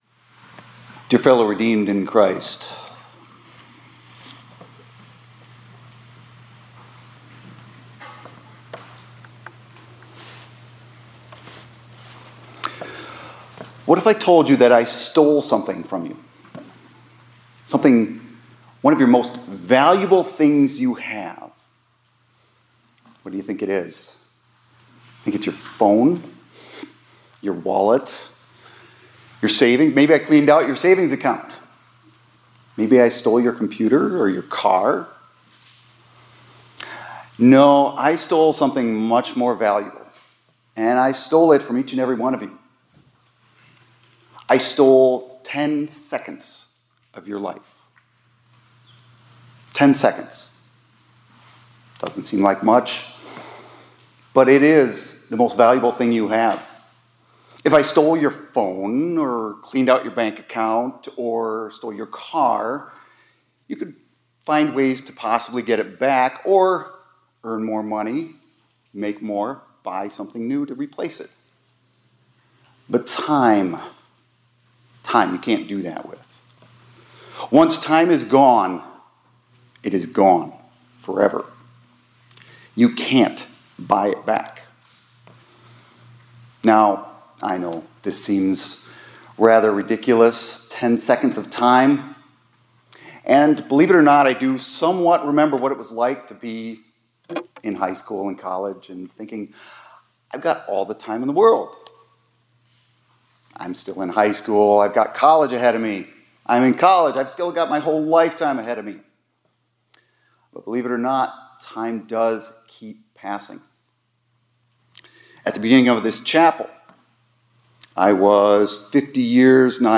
2025-01-17 ILC Chapel — Tick, Tick, Tick